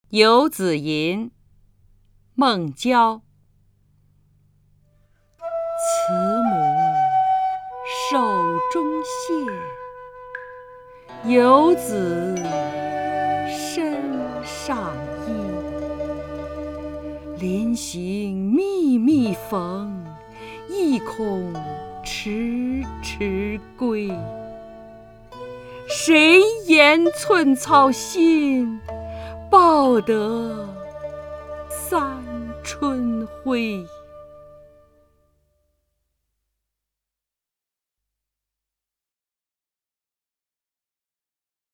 张筠英朗诵：《游子吟》(（唐）孟郊) （唐）孟郊 名家朗诵欣赏张筠英 语文PLUS